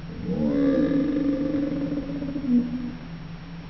Roar
roar.wav